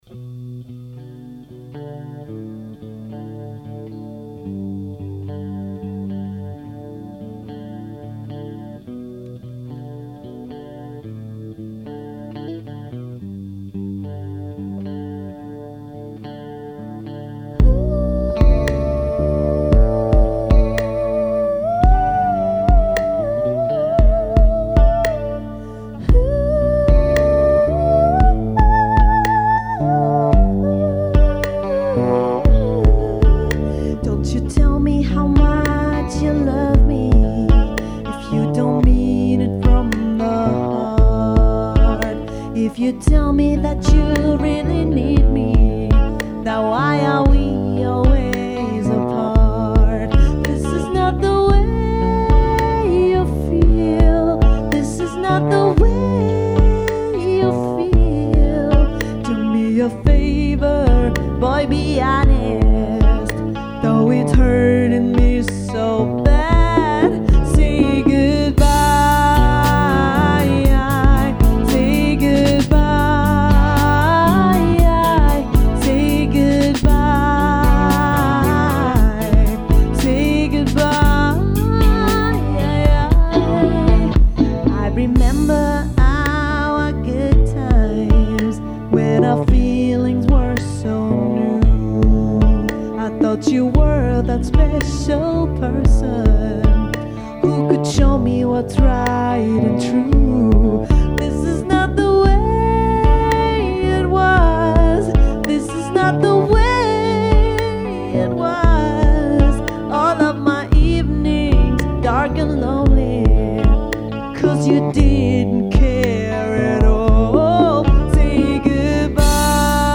Proberaummitschnitt